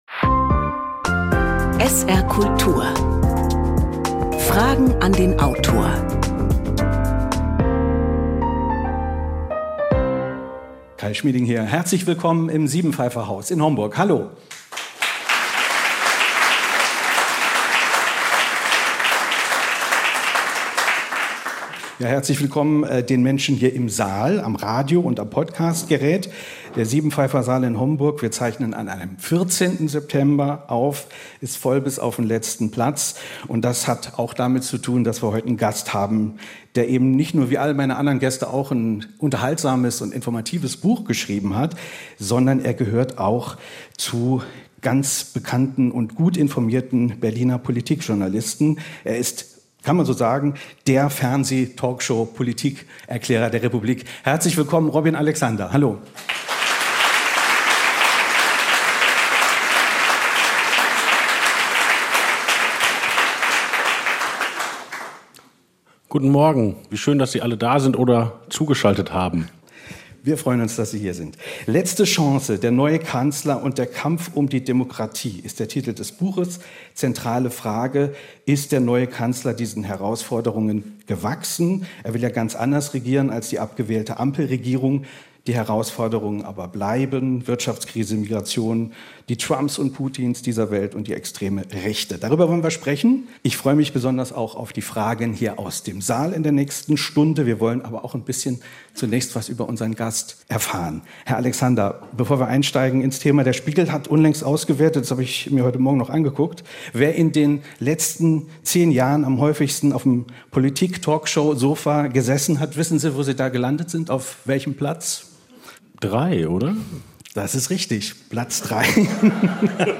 Im Gespräch vor Publikum im Rahmen der Buchmesse "HomBuch" in Homburg am 14. September reflektiert der Politikjournalist über seine Arbeit, gibt Einblick in seine Recherchen und seine eigene Motivation - und er spricht über die aktuellen Herausforderungen der neuen Regierung. Welche Lehren kann Friedrich Merz aus dem Scheitern der Ampel ziehen?